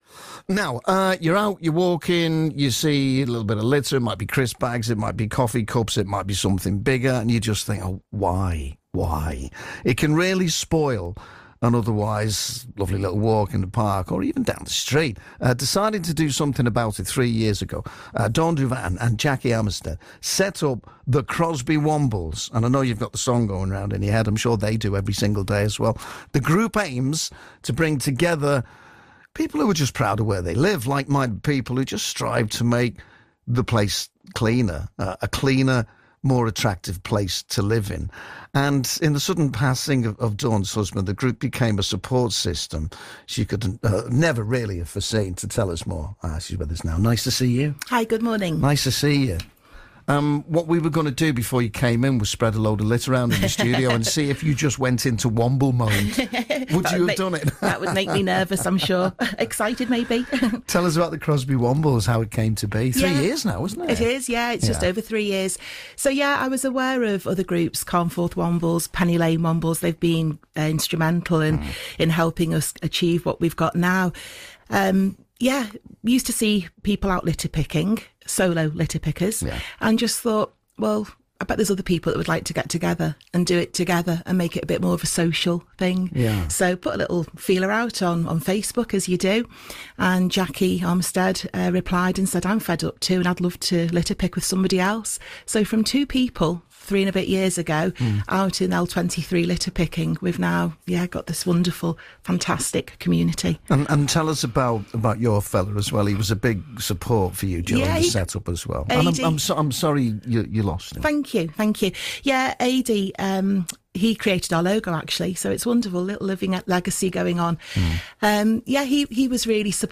Radio Merseyside Interview